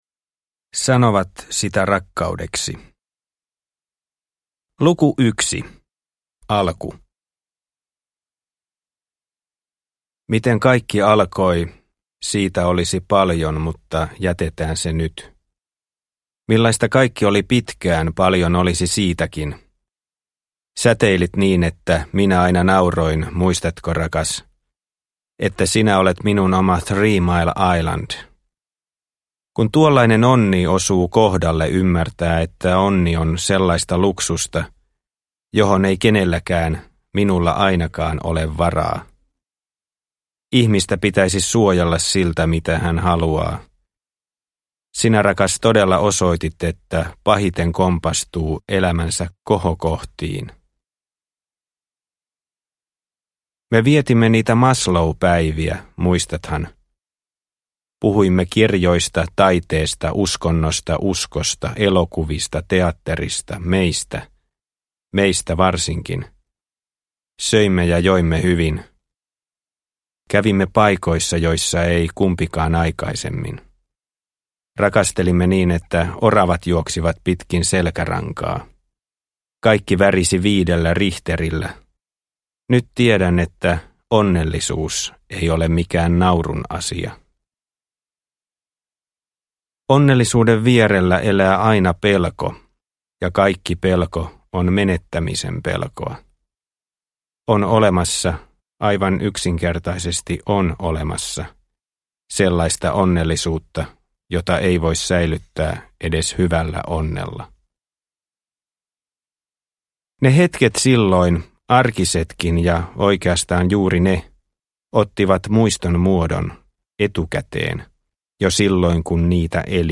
Sanovat sitä rakkaudeksi – Ljudbok – Laddas ner